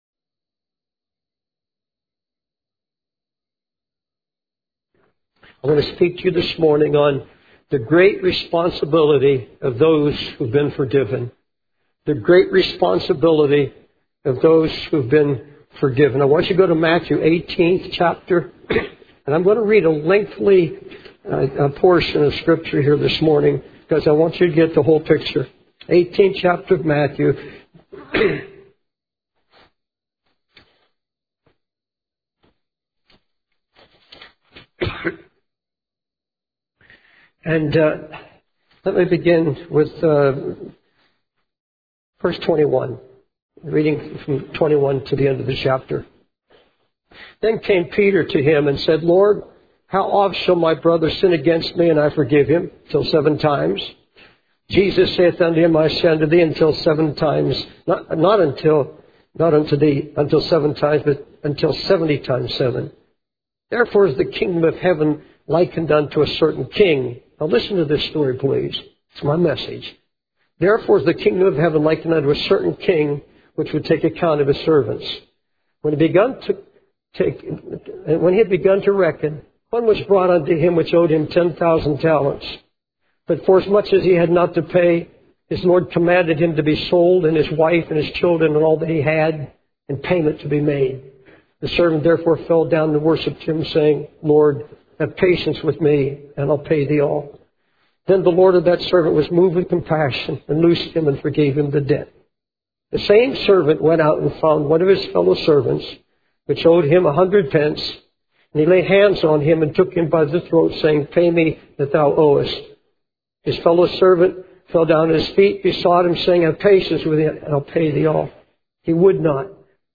This sermon emphasizes the great responsibility of those who have been forgiven, using the parable from Matthew 18 about the unforgiving servant. It highlights the importance of understanding and accepting God's grace, mercy, and forbearance in order to truly forgive others. The speaker urges the congregation to examine their hearts, repent, and extend forgiveness to others as they have been forgiven by God.